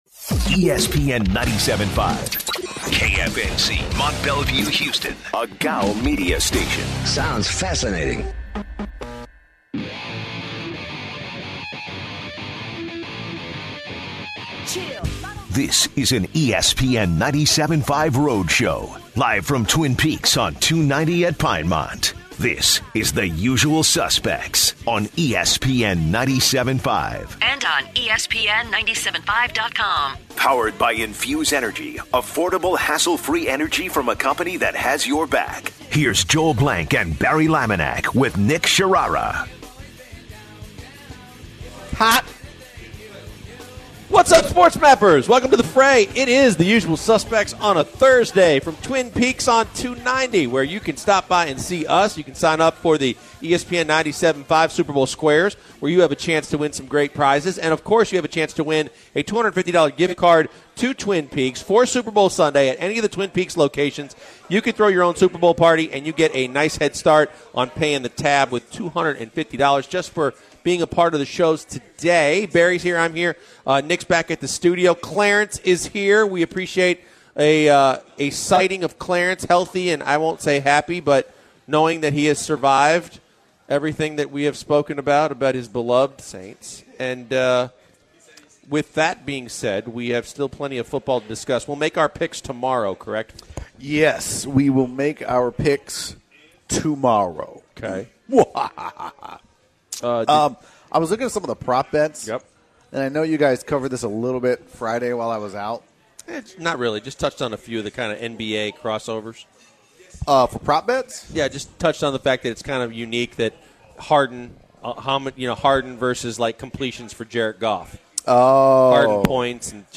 on location at Twin Peaks for the day